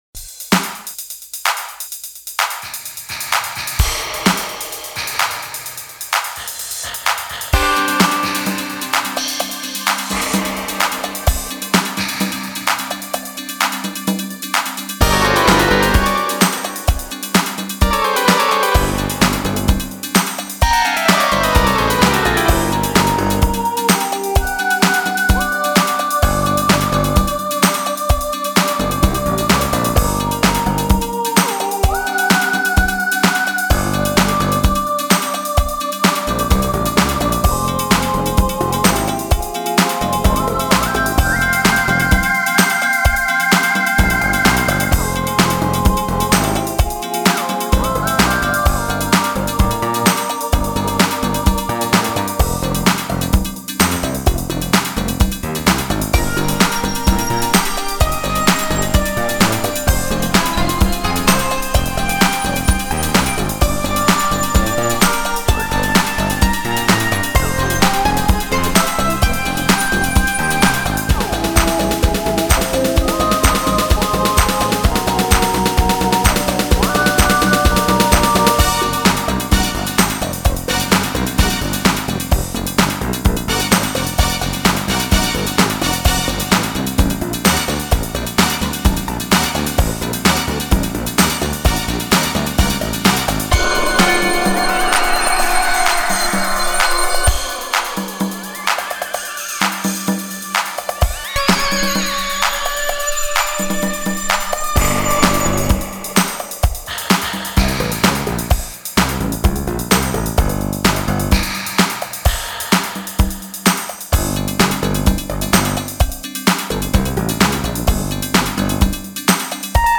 Halloween Scary Songs & Sounds